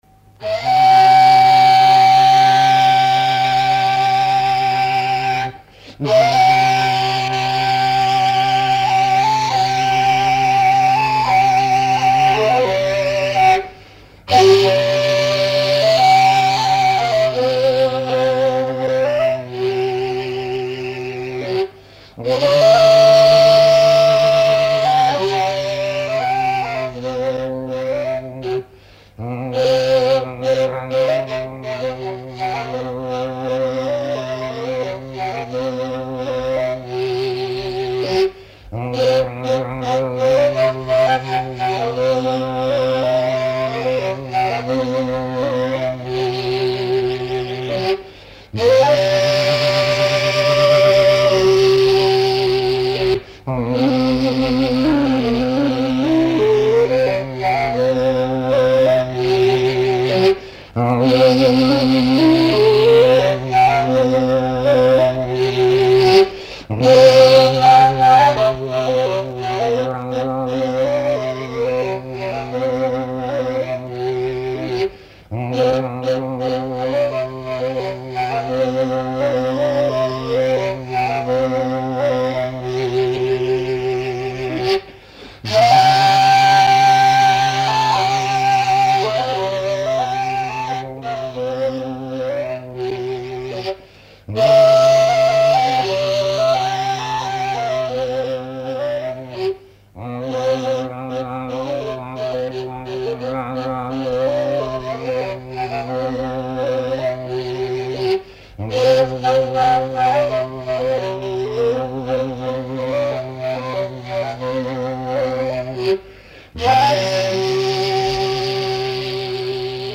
“ҠАРАҺАҠАЛ”, “Хәрби марш”,  “Ҡараһаҡал маршы”, башҡ. халыҡ инструменталь көйө.
Көй вариация формаһында, марш-бейеү характерында; тема тоника квартсекстаккорды өндәре аша бирелеп квинта тонында тамамлана.
”КАРАХАКАЛ”, наигрыш Аудио